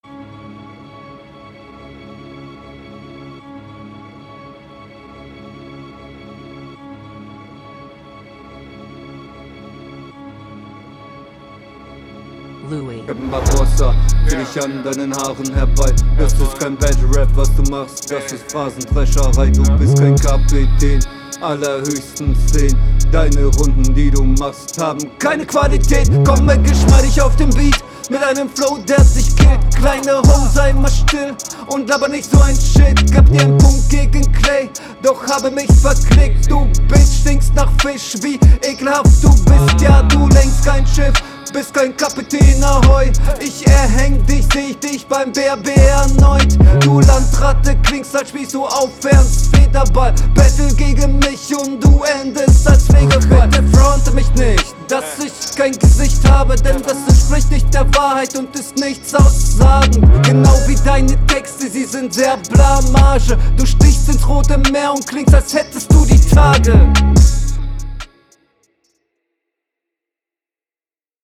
''Keine Qualität'' klingt SO krass, der Rest leider nicht ganz so wild.